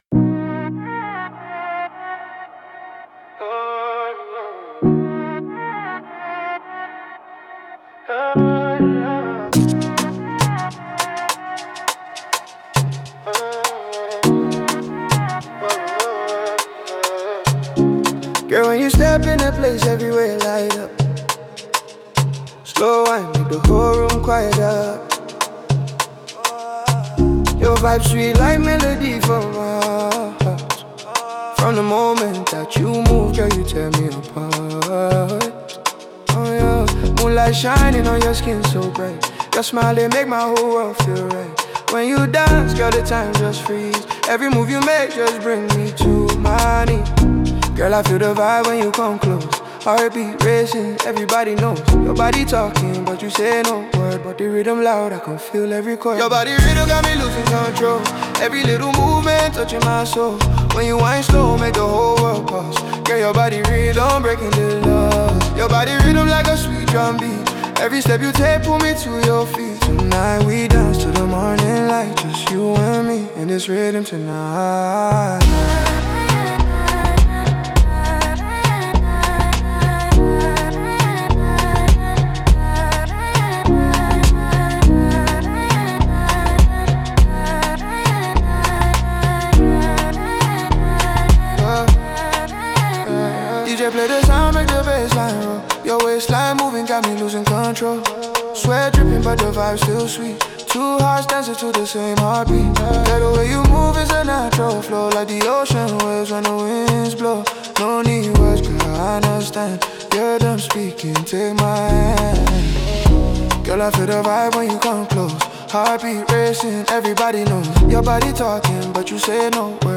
Afrobeats 2026 Non-Explicit